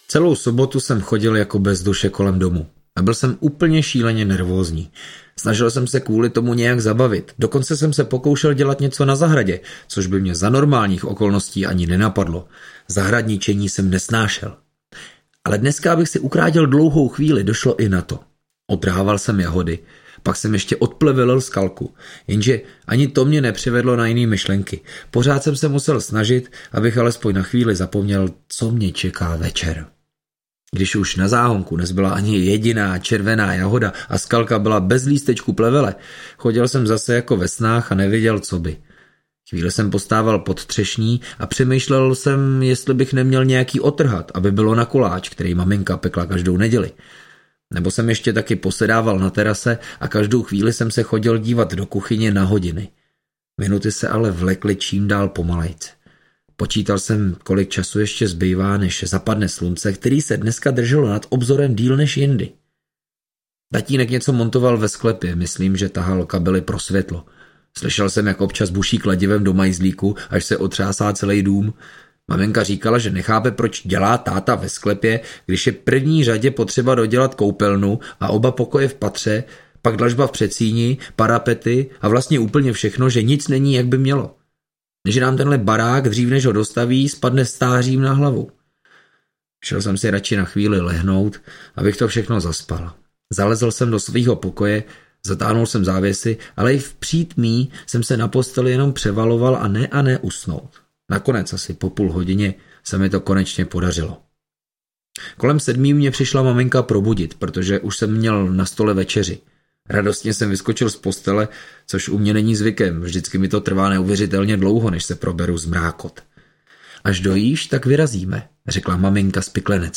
Proč blijou sloni audiokniha
Ukázka z knihy
• InterpretLukáš Hejlík